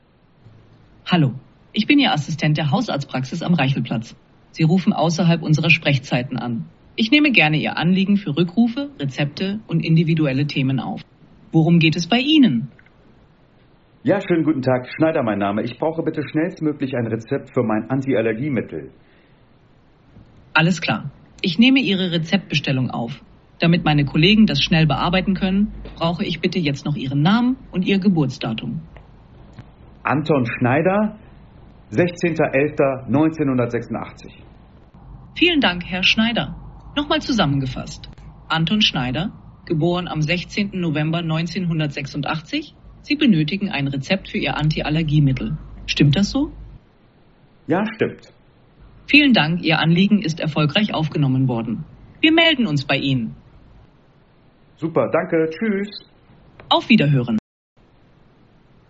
Aber wie klingt der KI-Bot am Telefon? hier ein paar Praxisbeispiele
Smarter Anrufbeantworter
arztpraxis-ki-anrufbeantworter.mp3